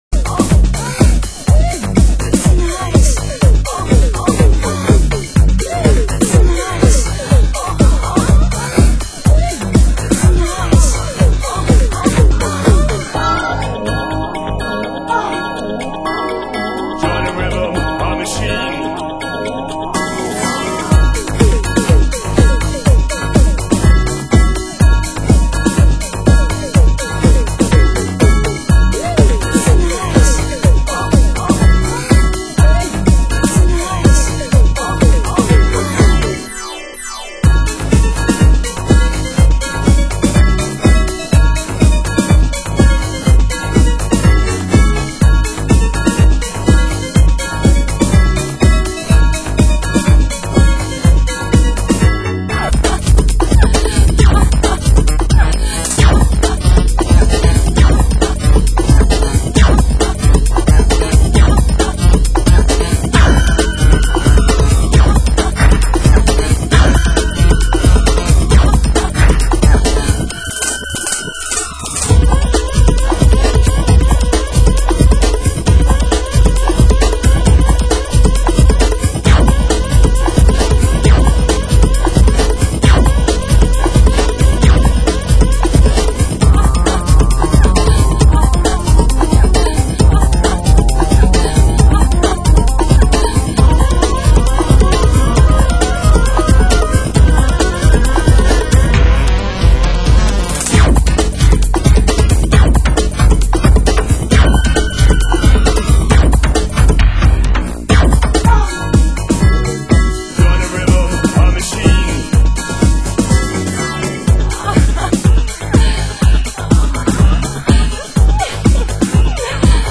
Genre: Euro Rave (1990-92)